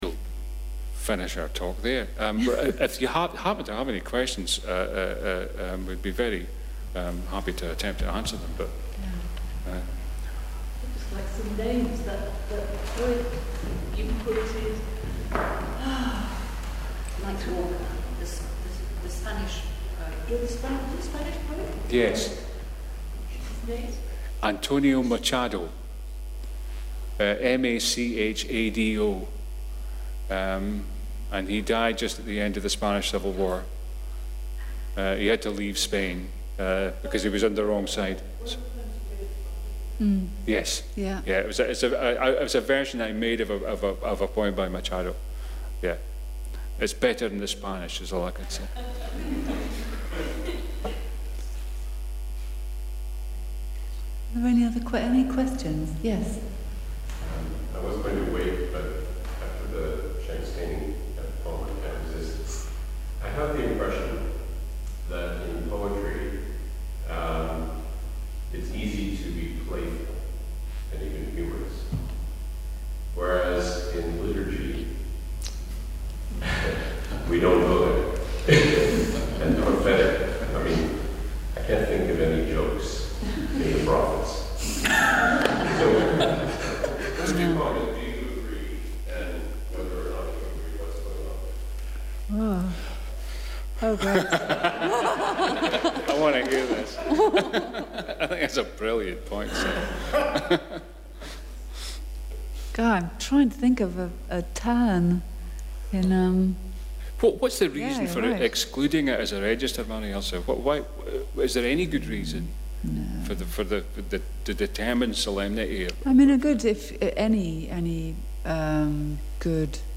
THE SACRED WORD A CONVERSATION ABOUT POETRY AND THE SPIRIT
[Recordings made 19 March, 2018 at the ELCG]
Note: we are very sorry about background hum on the voice microphones - - it comes from our sound system so it unfortunately cannot be removed from the recording.